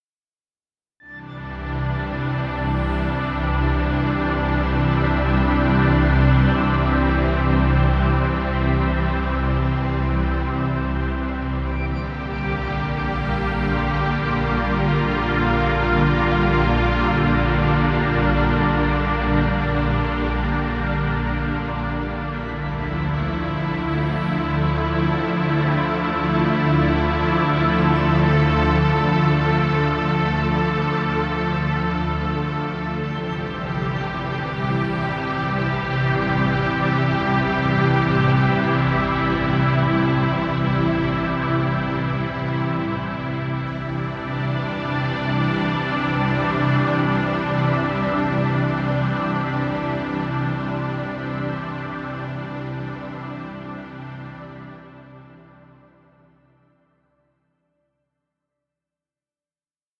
Relaxing music. Background music Royalty Free.